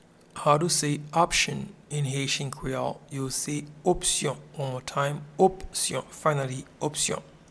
Pronunciation and Transcript:
Option-in-Haitian-Creole-Opsyon.mp3